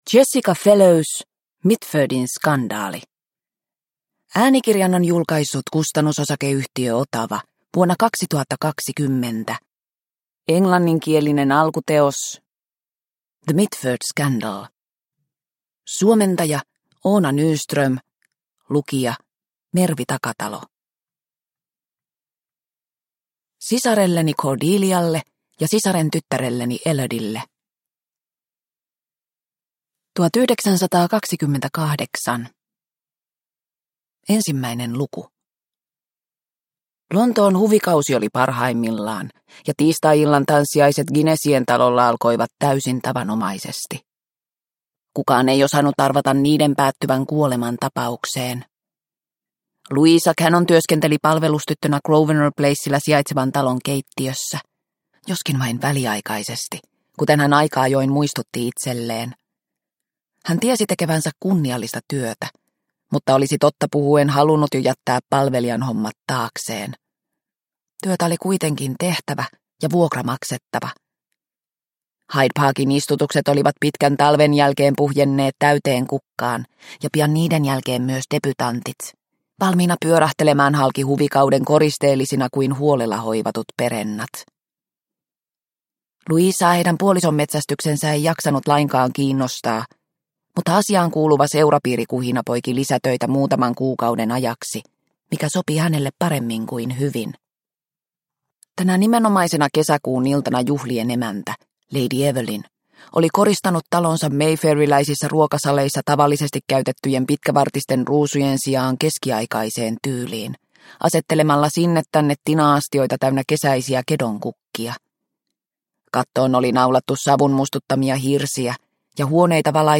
Mitfordin skandaali – Ljudbok – Laddas ner